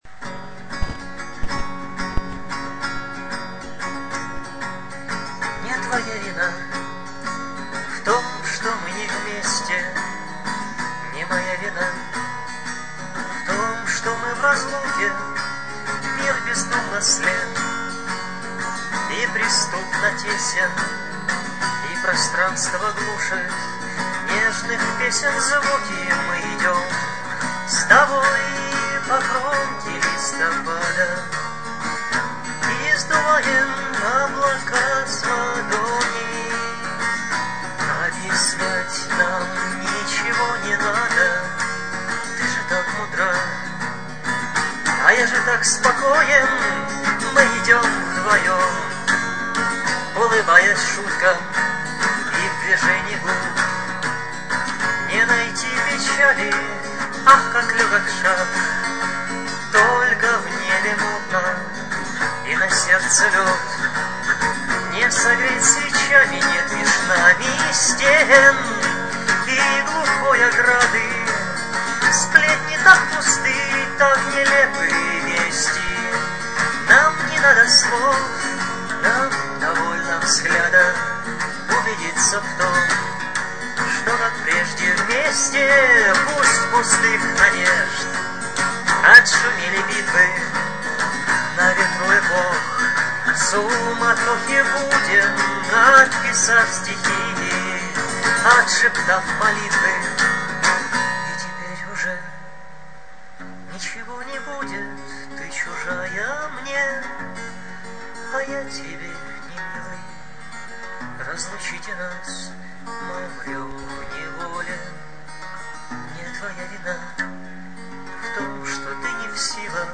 Как спел один раз, так спел и точка... :)